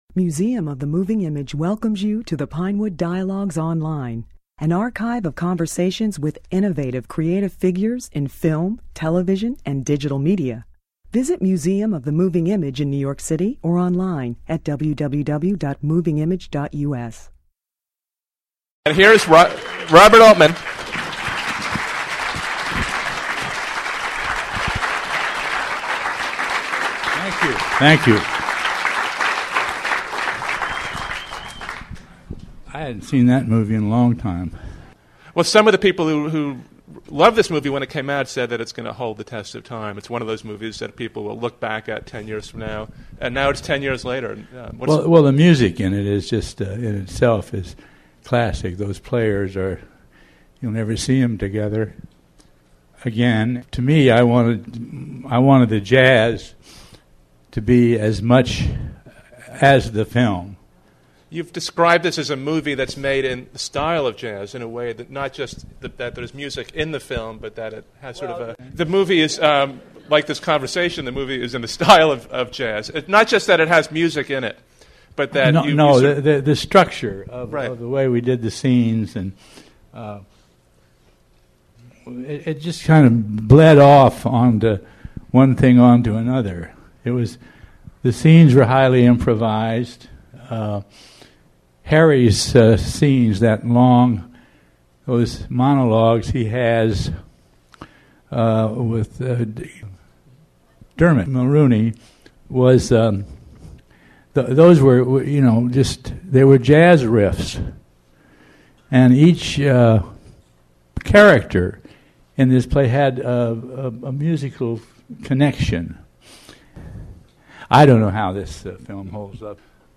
A month after winning an Honorary Academy Award, Altman opened a 22-film retrospective of his career by speaking at the Museum following a screening of Kansas City , a panoramic and jazz-like melodrama about politics, race, crime, and the movies, which is set in Altman's home town.